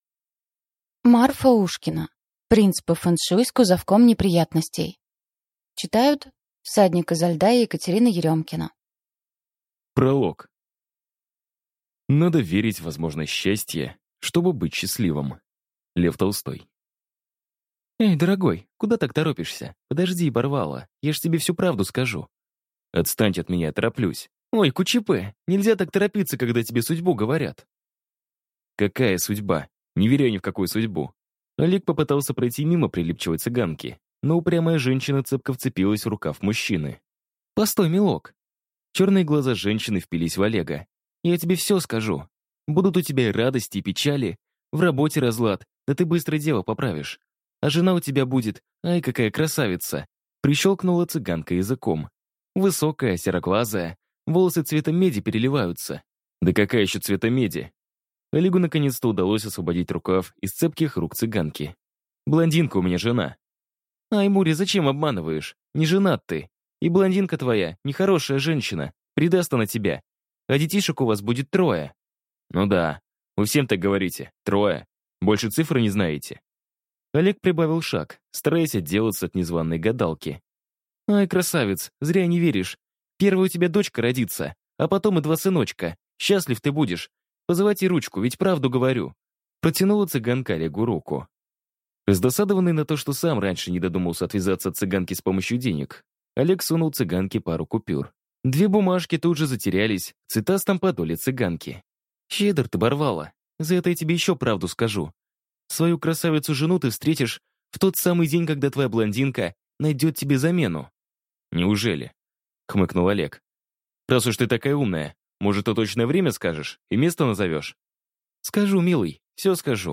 Аудиокнига Принц по фэншуй с кузовком неприятностей | Библиотека аудиокниг